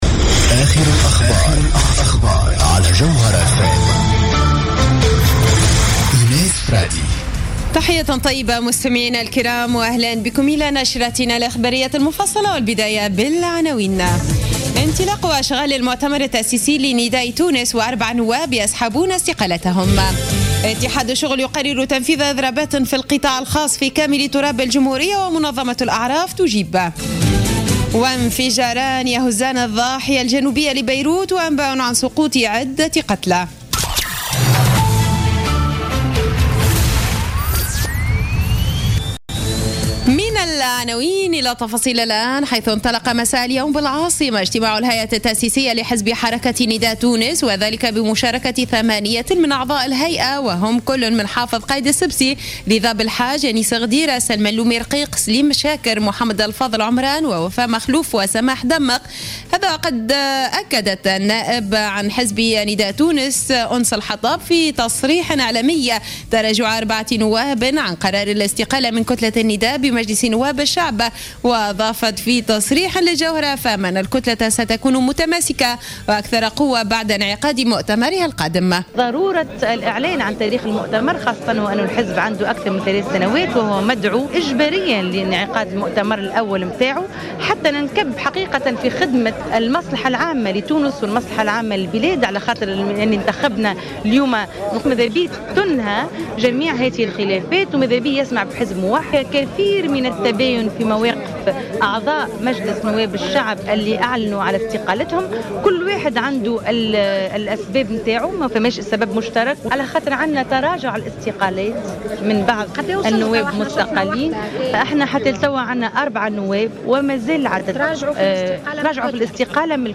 نشرة أخبار السابعة مساء ليوم الخميس 12 نوفمبر 2015